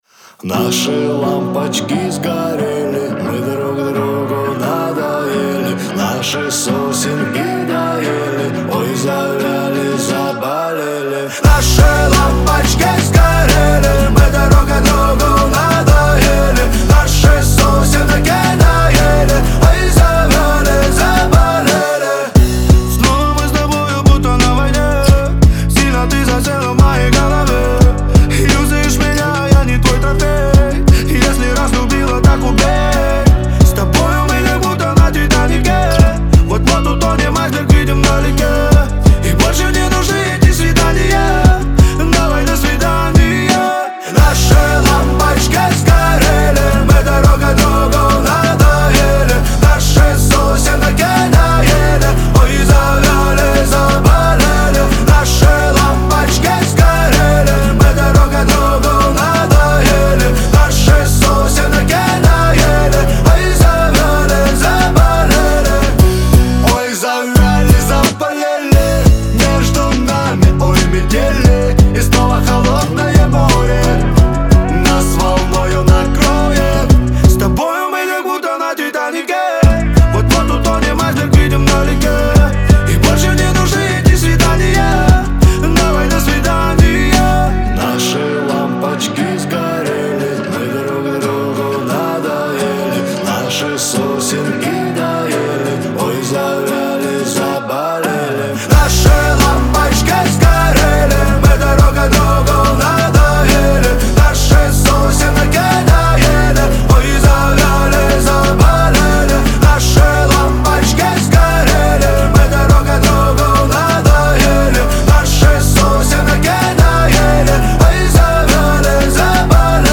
Танцевальная музыка
Dance музыка